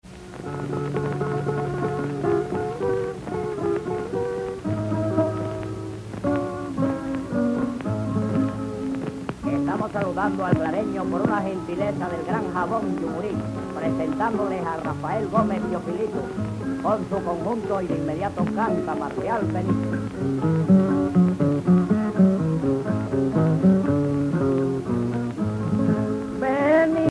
Punto
Pièce musicale inédite